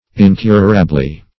Incurably \In*cur"a*bly\, adv.